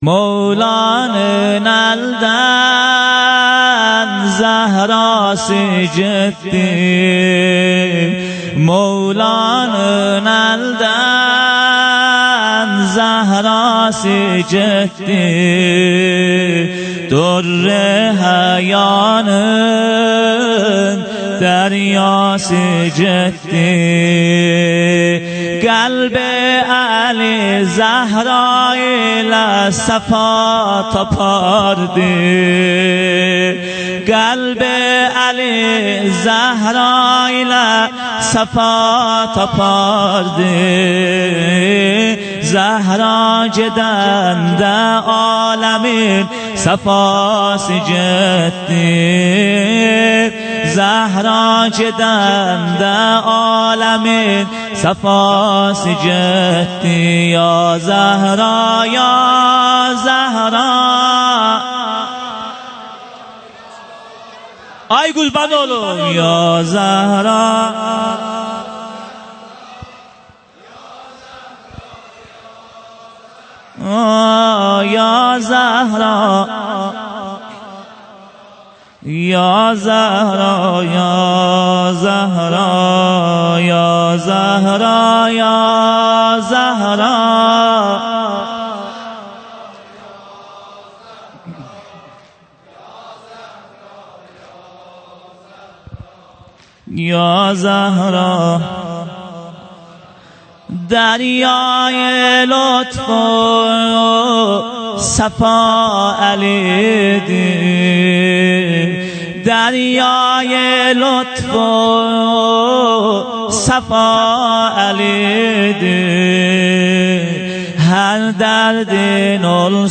شام غریبان
سینه زنی